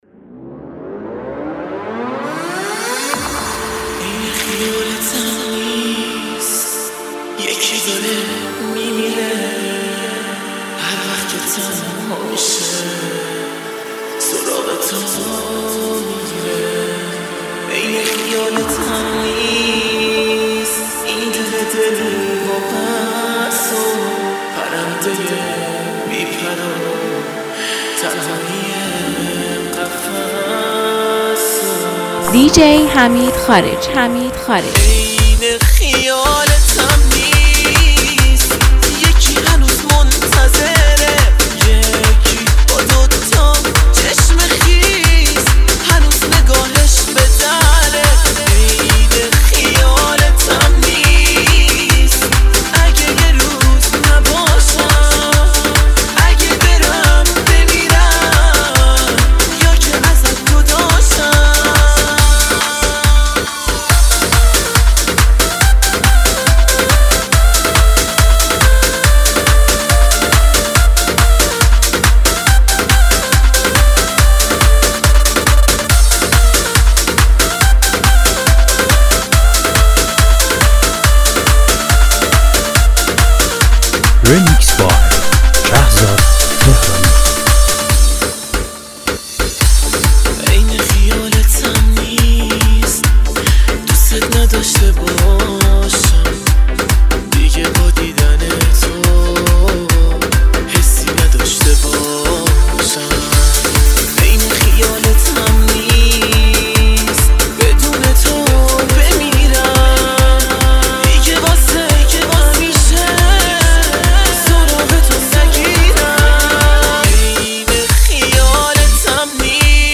ریمیکس شاد و پرانرژی مخصوص دورهمی‌های شما.